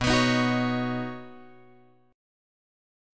Badd9 chord {7 x 9 8 7 9} chord